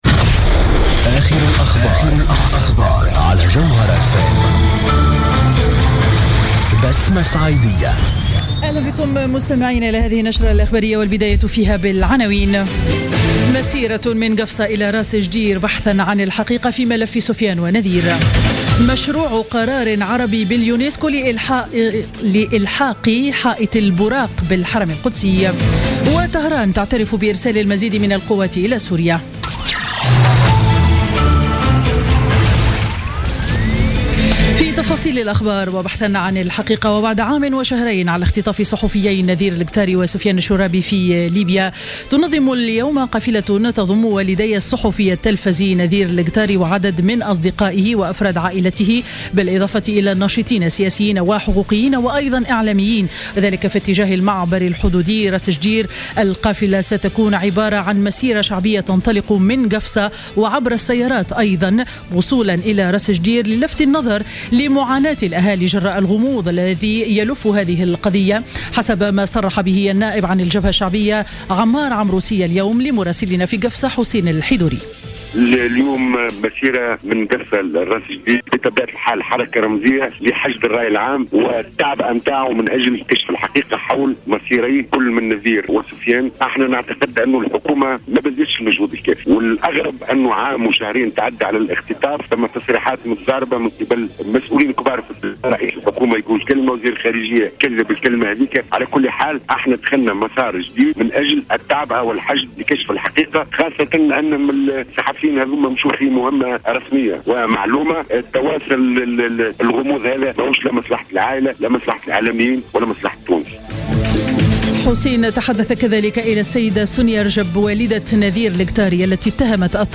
نشرة أخبار منتصف النهار ليوم الأربعاء 21 أكتوبر 2015